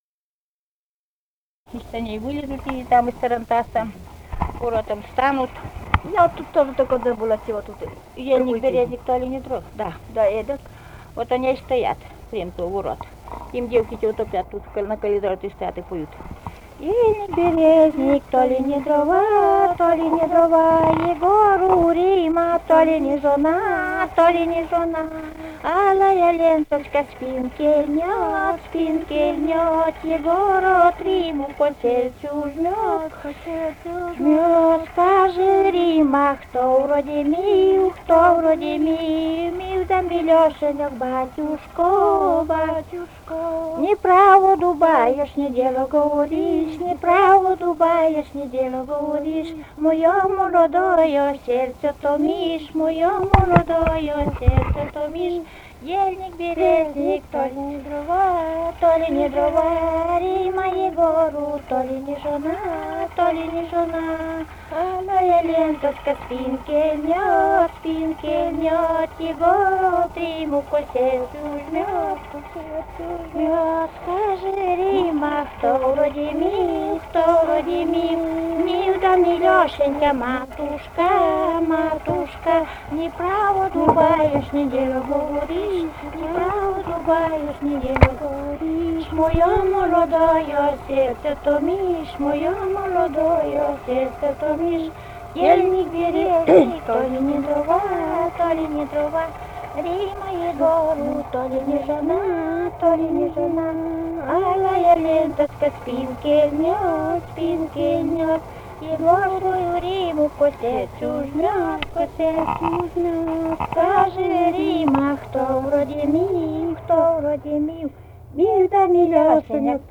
Этномузыкологические исследования и полевые материалы
Вологодская область, д. Усть-Вотча Марьинского с/с Вожегодского района, 1969 г. И1132-29